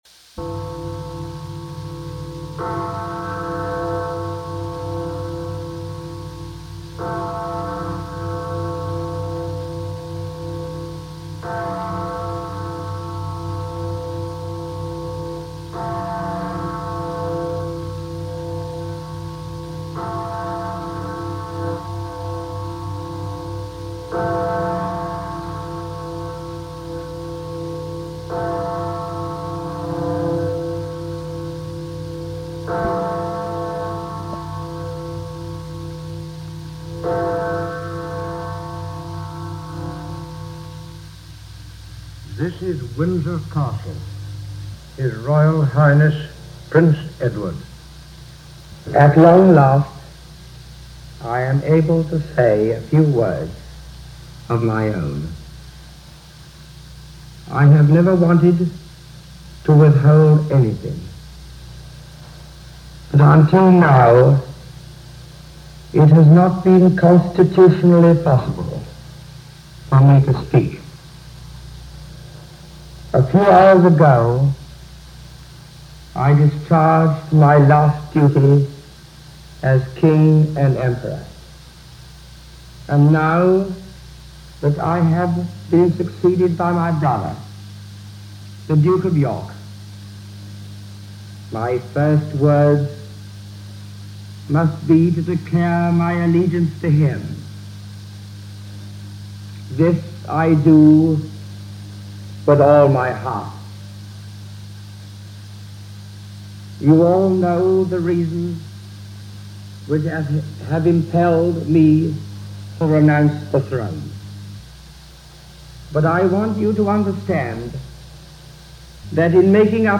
Edward VIII Abdicates - George VI Becomes King - December 11, 1936 - broadcast by the BBC and relayed around the world.